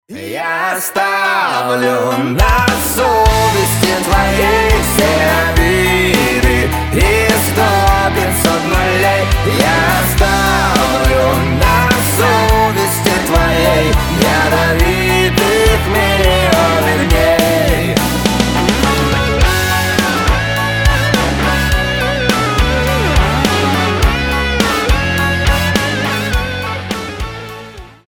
• Качество: 320, Stereo
дуэт
эстрадные